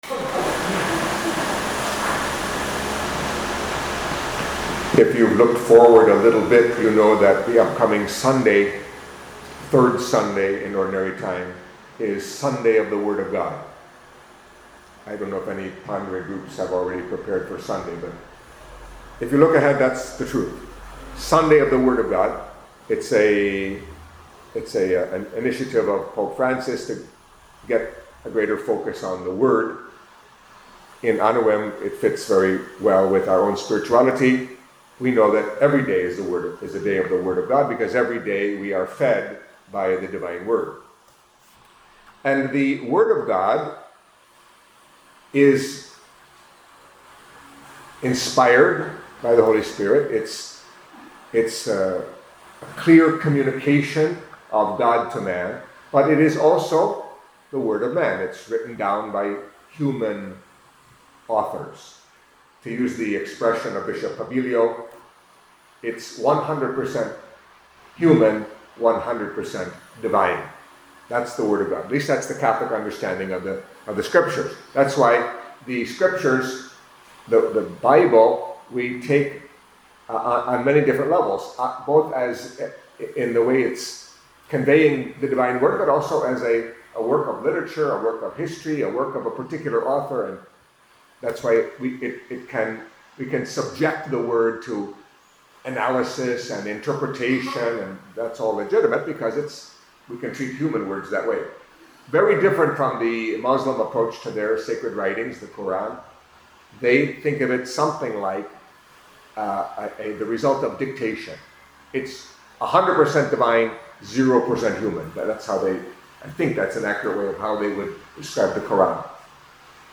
Catholic Mass homily for Friday of the Second Week in Ordinary Time